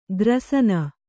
♪ grasana